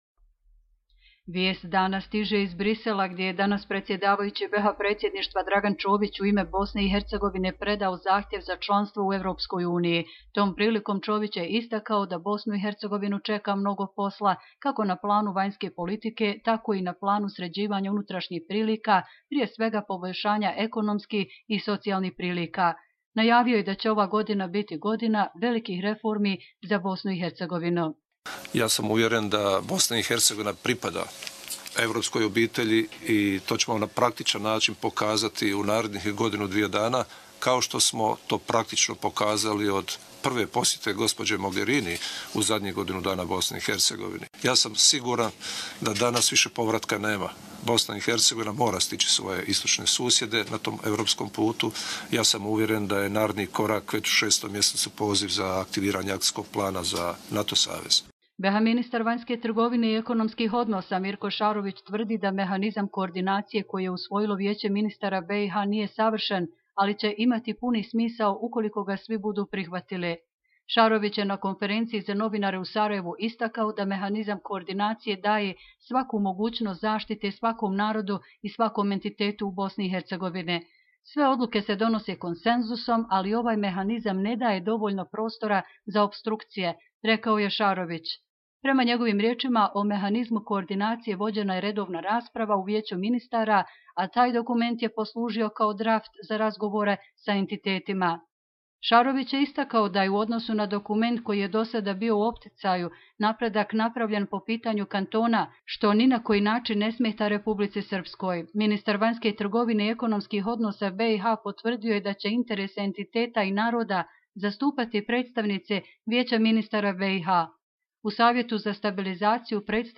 Audio izvještaji
govori predsjedavajući Predsjedništva BiH Dragan Čović: